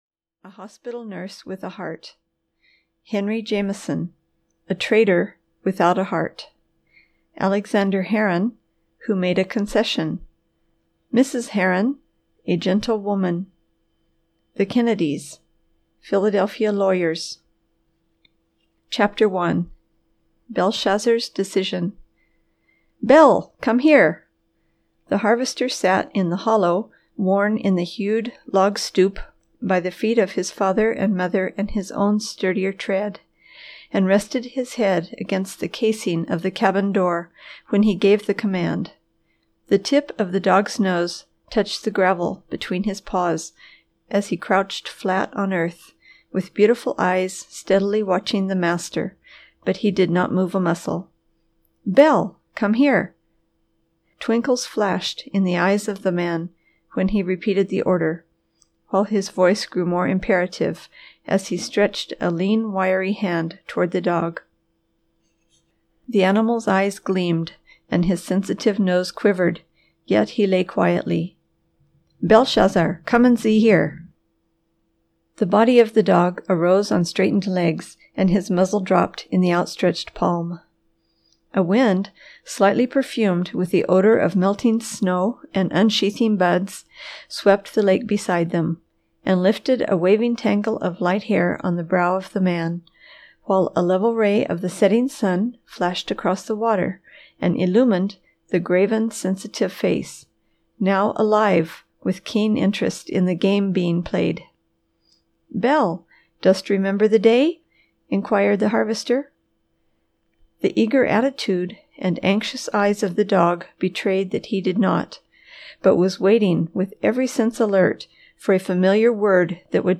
The Harvester (EN) audiokniha
Ukázka z knihy